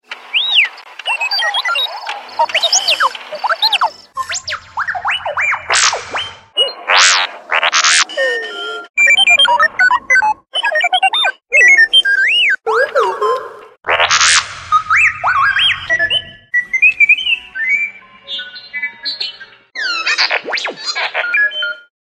r2d2-sounds-mp3.mp3